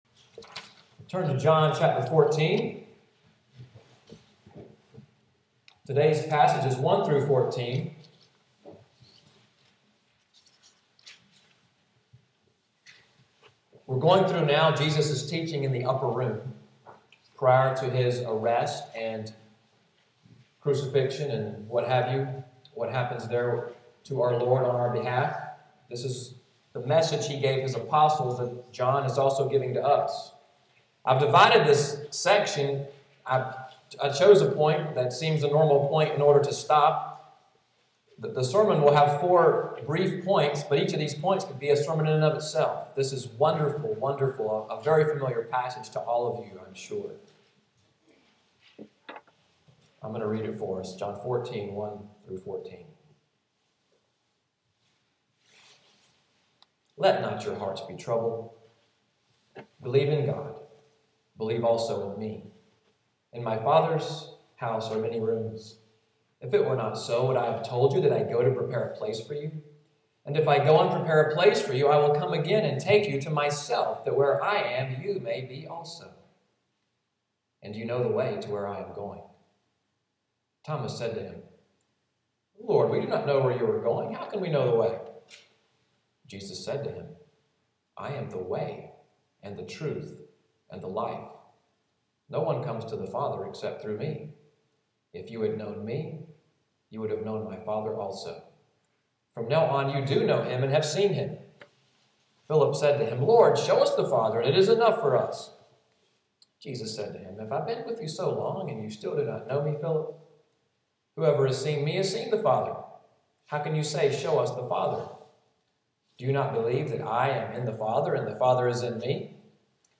Audio from the sermon, “This Way …“, preached November 24, 2013